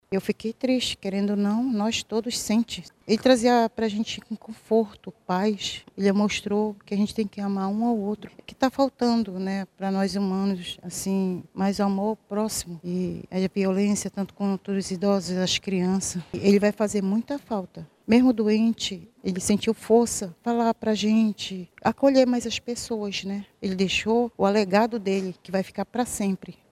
No Santuário Nossa Senhora Aparecida, na zona sul de Manaus, outro local de grande expressão da fé na cidade, fiéis se reuniram para prestar homenagens e rezar pelo descanso do pontífice.